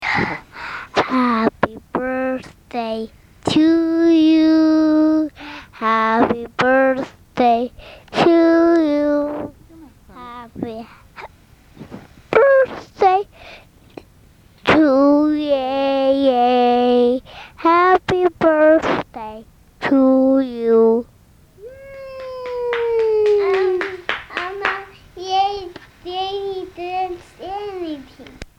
Ik ga een liedje voor je zingen.